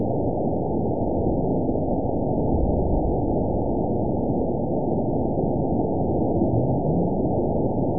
event 917488 date 04/05/23 time 15:41:19 GMT (2 years, 1 month ago) score 9.24 location TSS-AB01 detected by nrw target species NRW annotations +NRW Spectrogram: Frequency (kHz) vs. Time (s) audio not available .wav